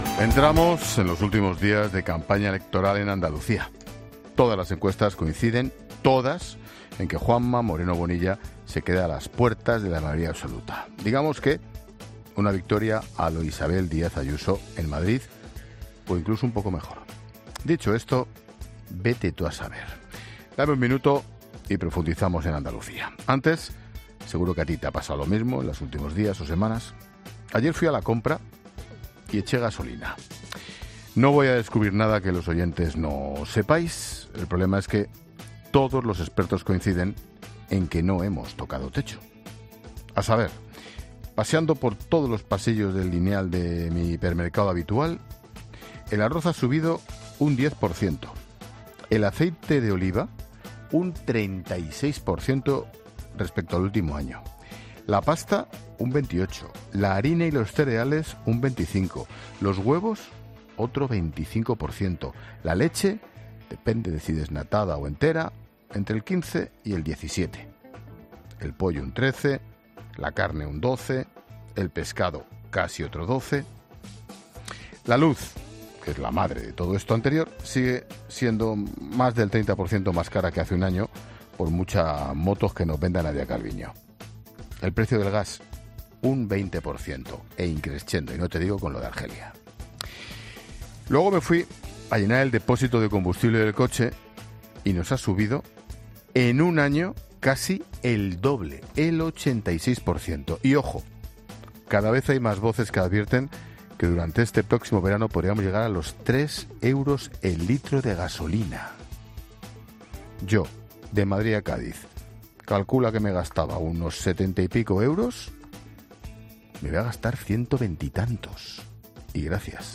Monólogo de Expósito
El director de 'La Linterna' reflexiona sobre múltiples asuntos de actualidad en su monólogo